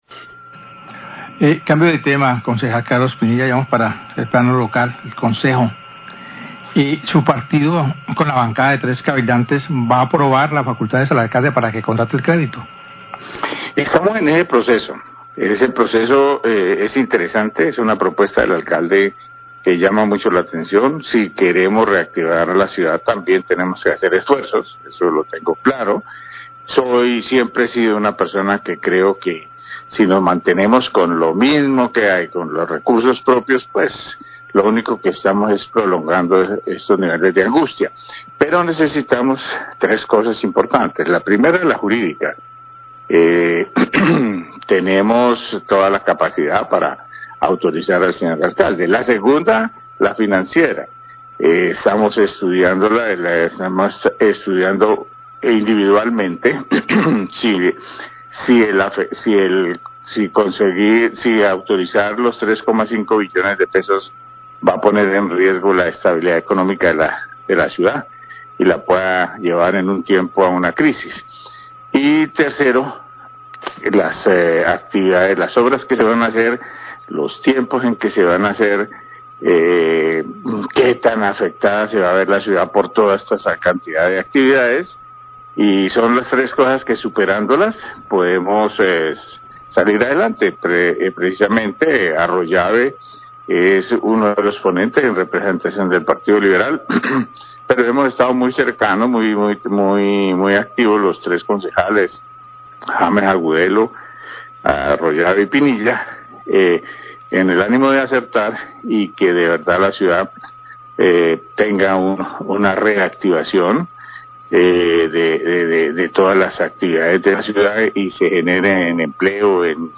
Radio
Concejal de Cali, Carlos Pinilla, habló acerca de la posibilidad para aprobar facultades al alcalde Eder para solicitar un crédito. Manifestó que se está estudiando las finanzas de la ciudad y si el crédito pondría en riesgo a la ciudad financieramente, también los tiempos de ejecución de las obras.